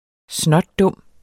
Udtale [ ˈsnʌdˈdɔmˀ ]